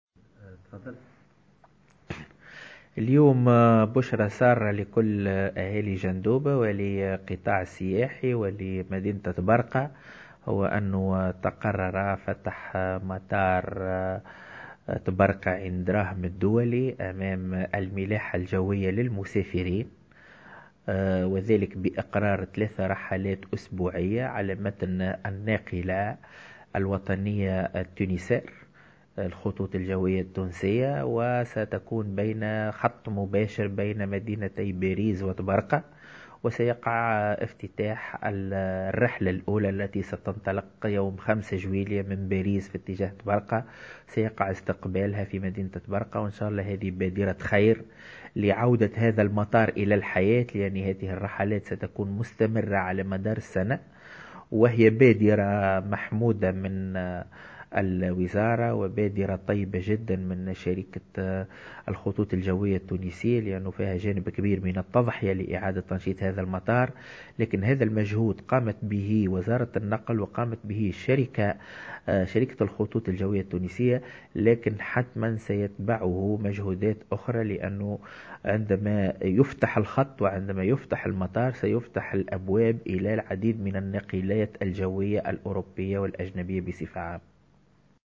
Le gouverneur de Jendouba, Akram Sabri, a indiqué dans une déclaration accordée aujourd’hui, mercredi 7 juin 2017, à Jawhara Fm, que l'aéroport international de Tabarka-Aïn Draham rouvrira ses portes bientôt.